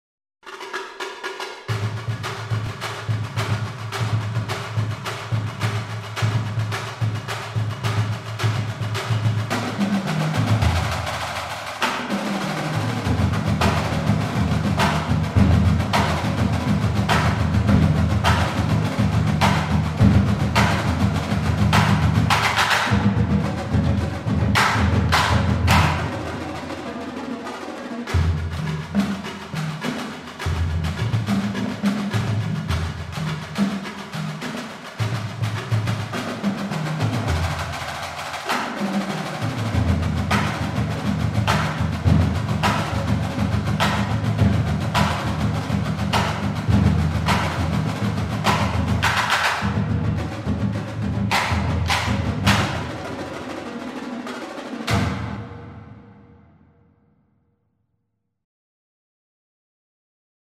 cadence.mp3